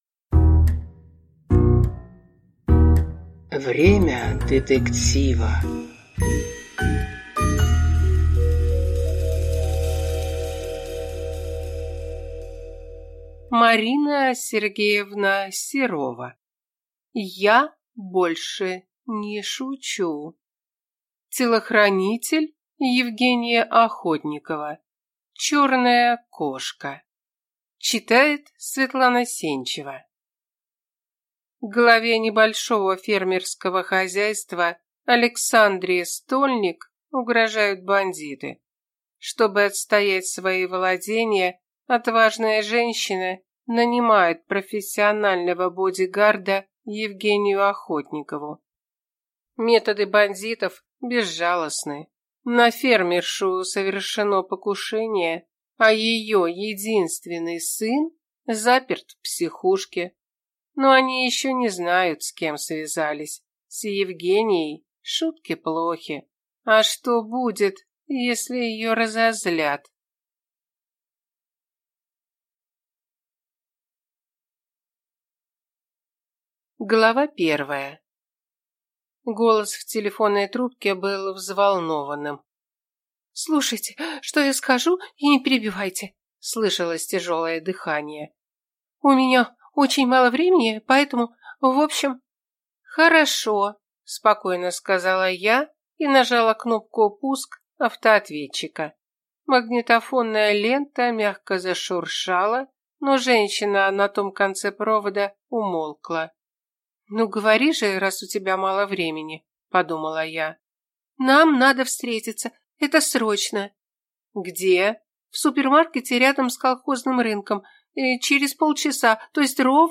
Аудиокнига Я больше не шучу | Библиотека аудиокниг
Прослушать и бесплатно скачать фрагмент аудиокниги